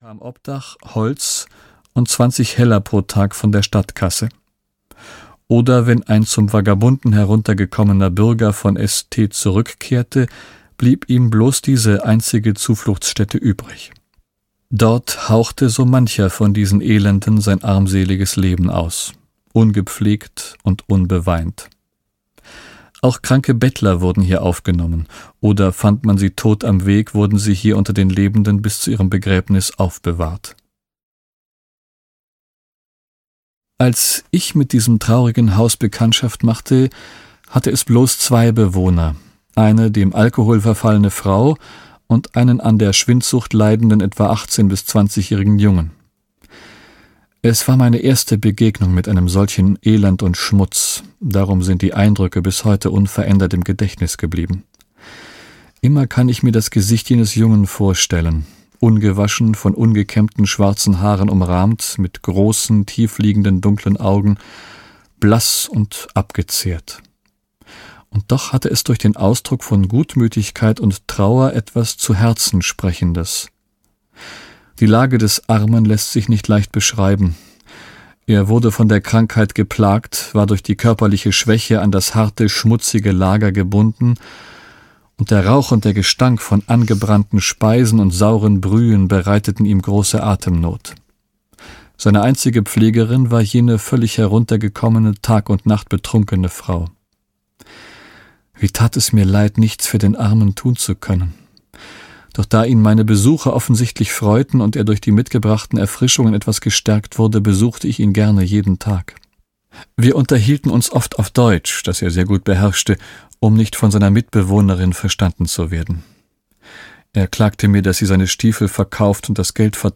Gestillte Sehnsucht - Kristina Roy - Hörbuch